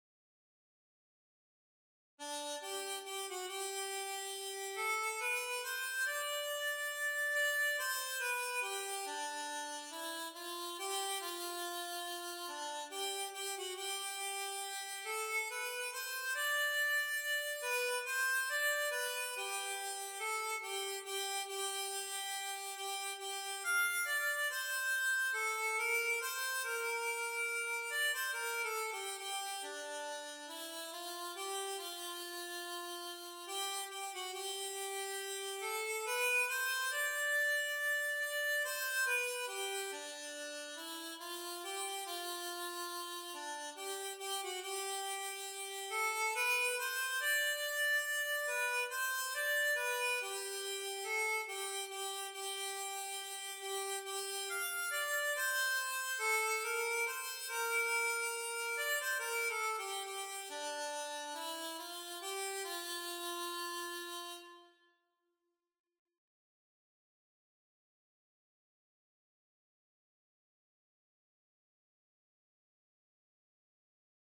Tempos may vary!